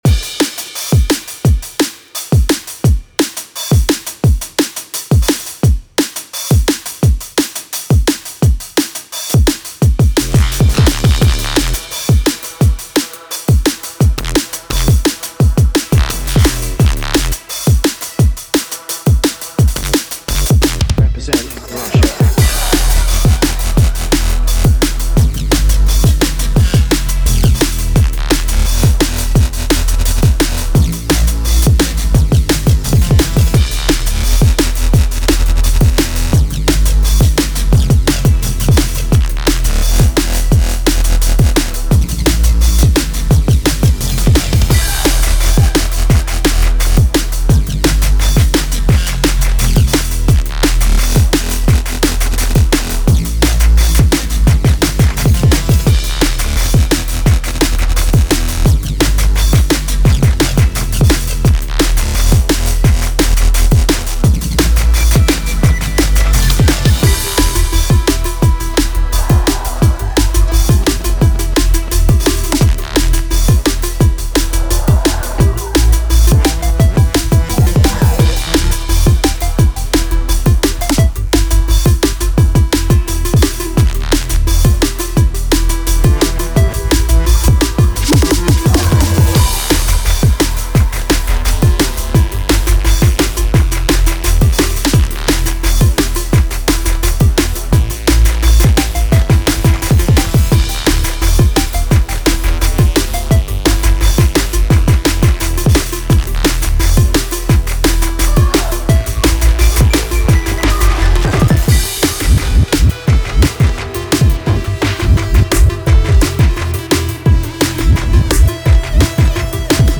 Game scream (Neurofunk